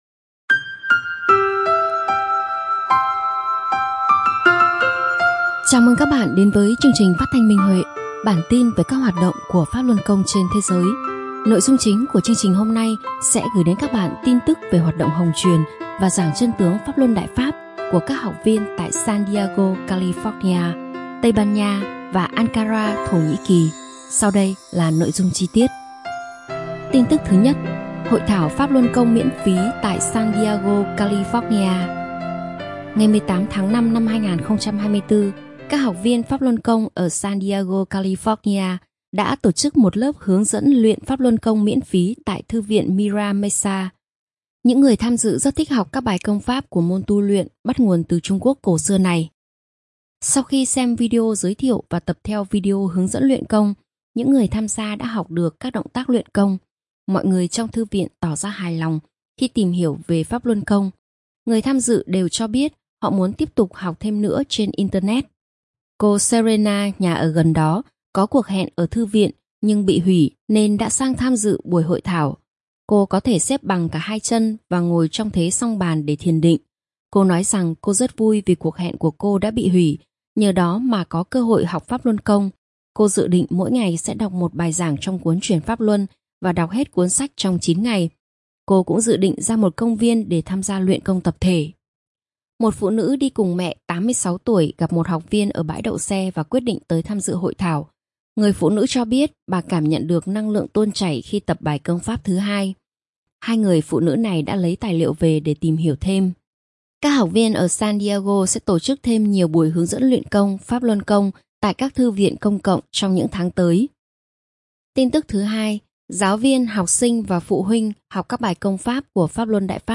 Phát thanh Minh Huệ: Tin tức về Pháp Luân Đại Pháp trên thế giới – Ngày 28/05/2024